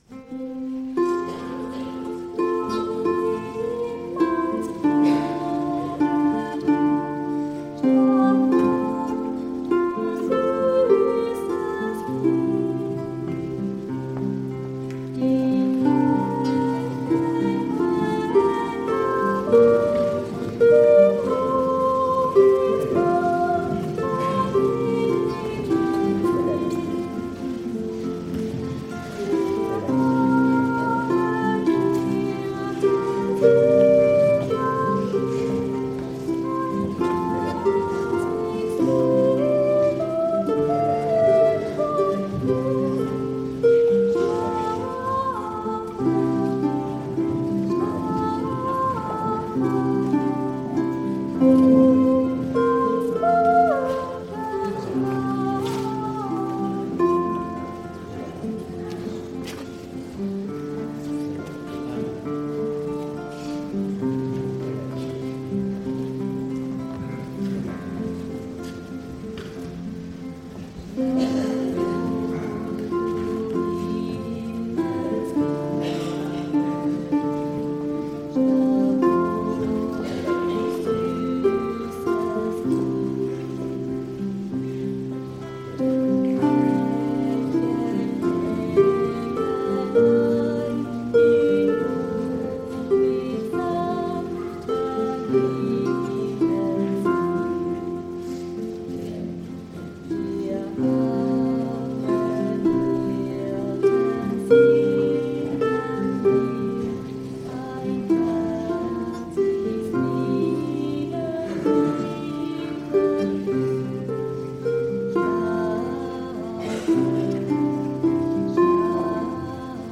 Die Lieder aus dem Gottesdienst
Instrumental Harfe + Gitarre - Schlafe, träume
Instrumental_Harfe_Gitarre_-_Schlafe_traeume_mp3